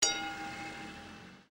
Daarnaast heb ik ook het einde van de bel nu eens goed kunnen 'vangen'
bel_laag_einde.mp3